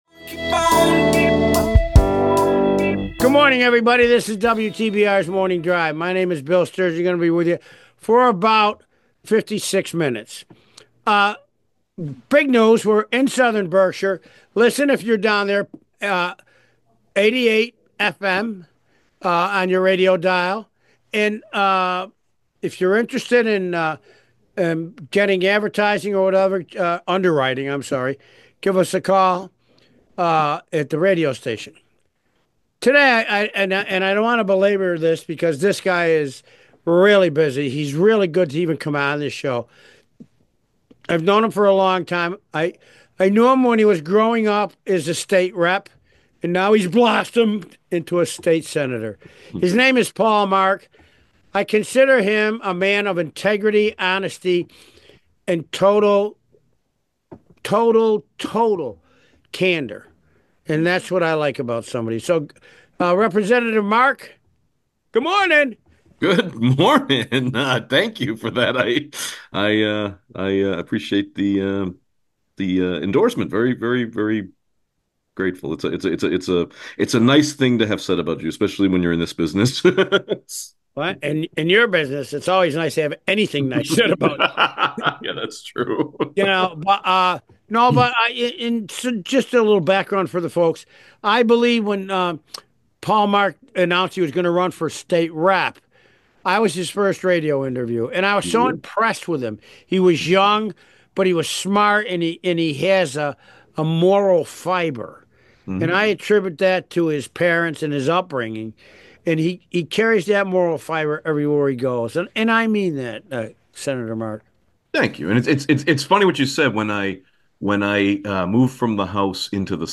This week we start with special guest, State Senator Paul Mark.